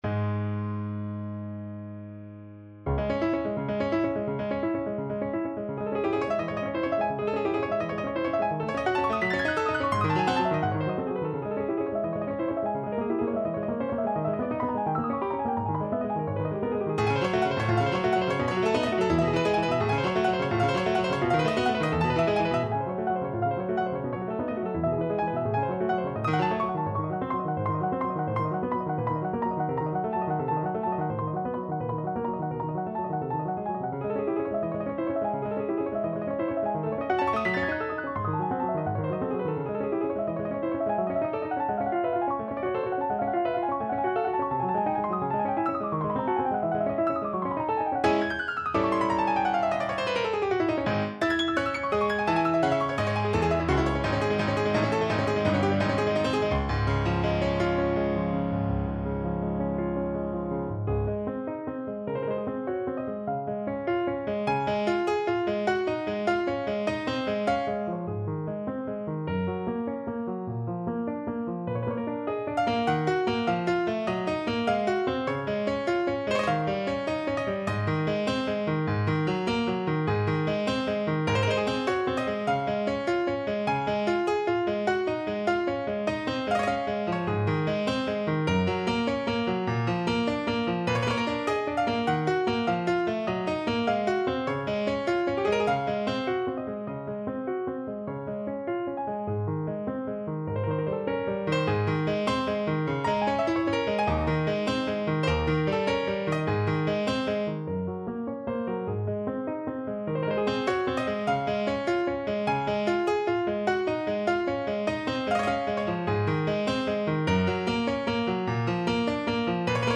No parts available for this pieces as it is for solo piano.
Allegro agitato (View more music marked Allegro)
Piano  (View more Advanced Piano Music)
Classical (View more Classical Piano Music)